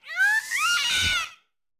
Cri de Fulgulairo dans Pokémon Écarlate et Violet.